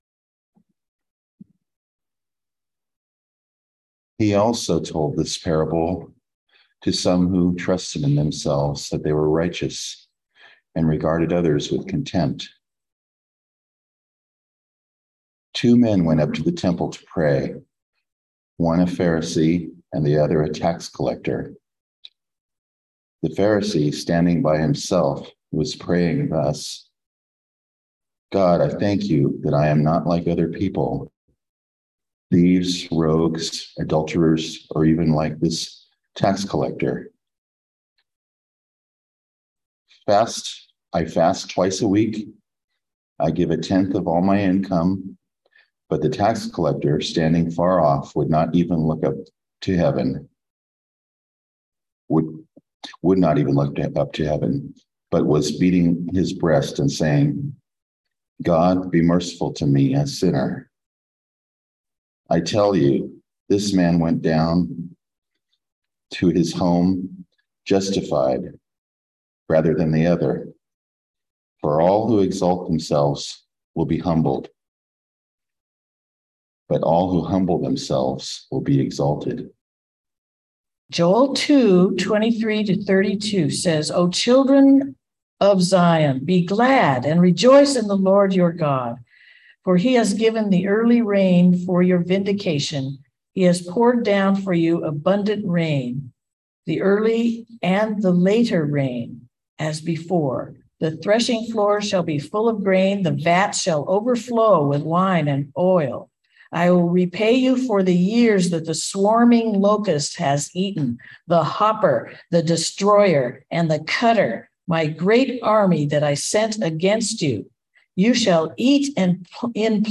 Message for October 23, 2022
Listen to the most recent message from Sunday worship at Berkeley Friends Church, “Lord, Have Mercy.”